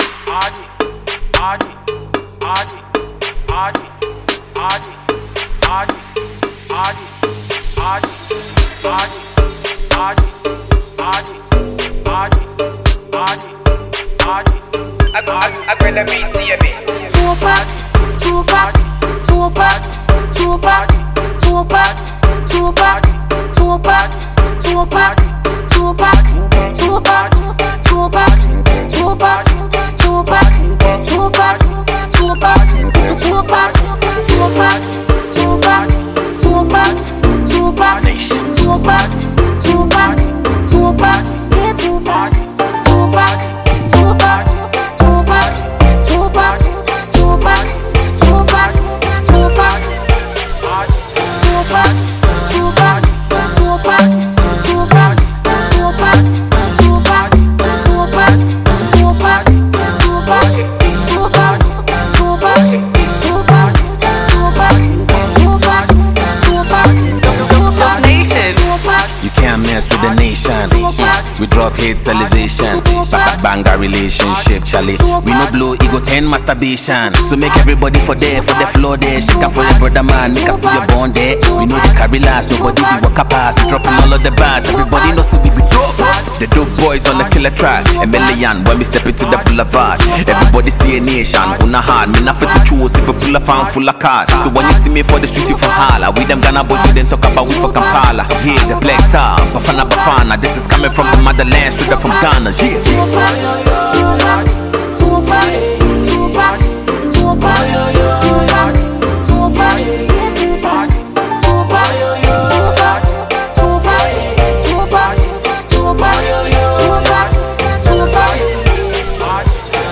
Ghana MusicMusic
Ghanaian musical duo of identical twin brothers